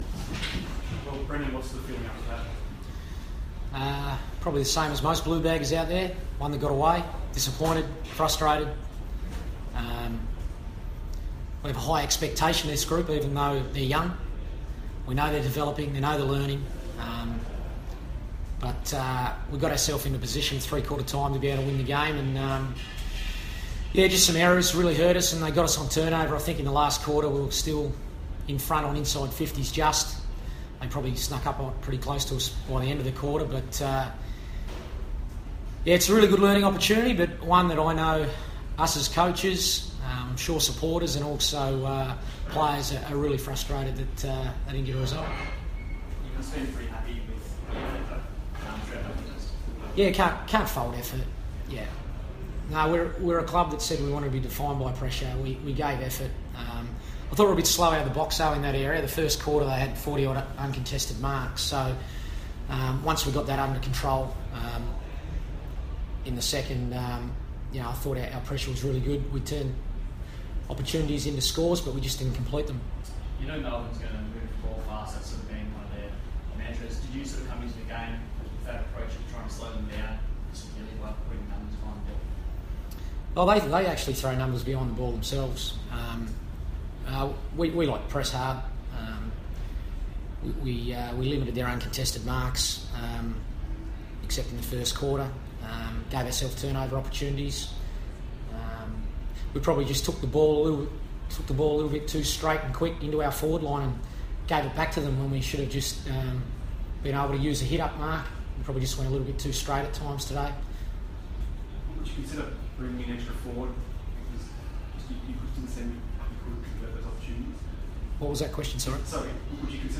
Carlton coach Brendon Bolton fronts the media after the Blues' 22-point loss to Melbourne.